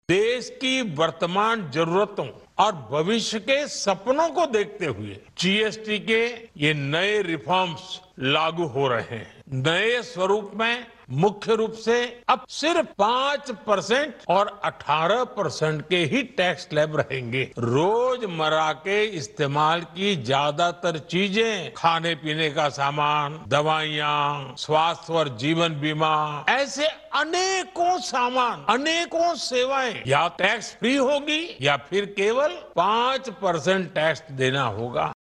(BYTE: PM NARENDRA MODI)